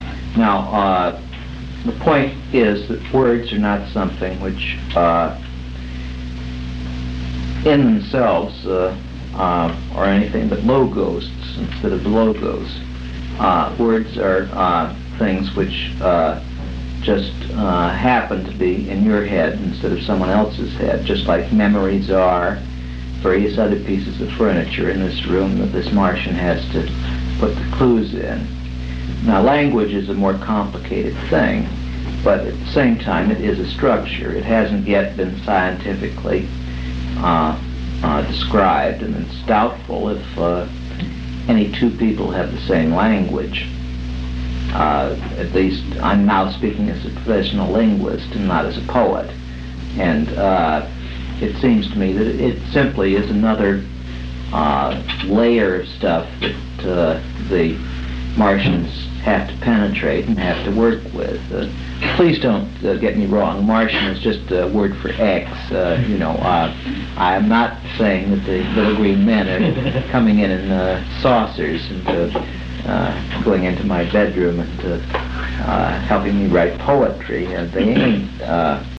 ∞ Jack Spicer, Vancouver Lecture 1 - June 13, 1965